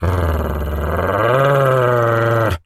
dog_growl_03.wav